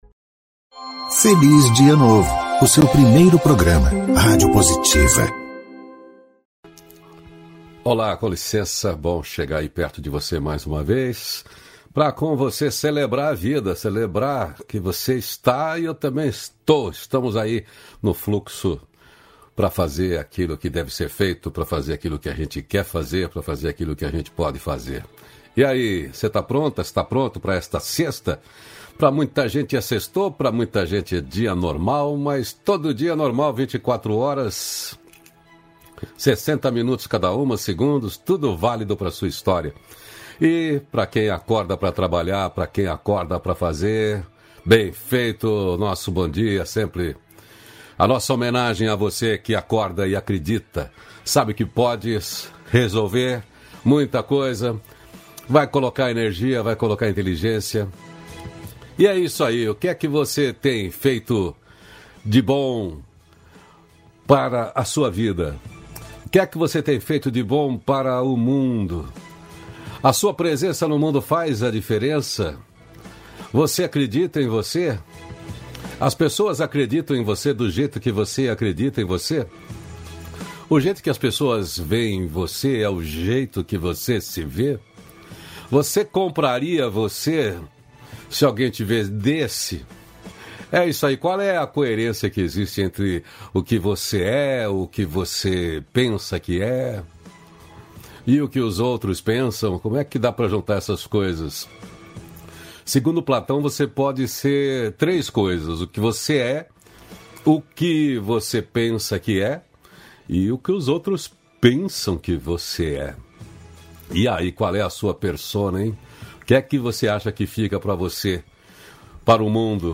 319-feliz-dia-novo-entrevista.mp3